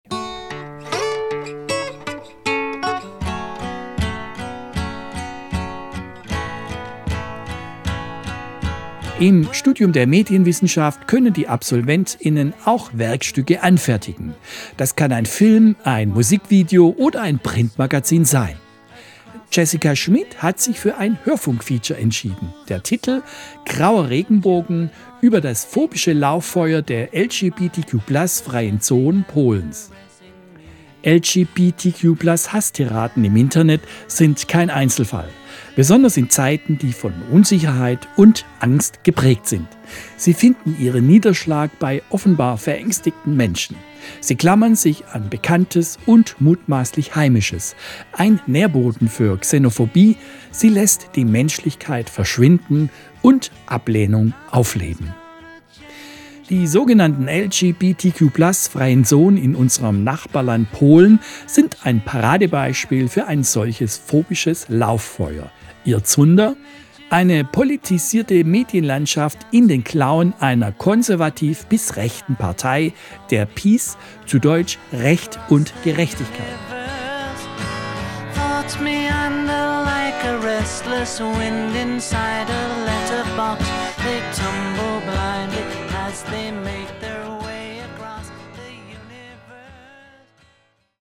Hörfunkfeature: Der Graue Regenbogen. Die LGBTQ+ -freie Zonen Polens (587)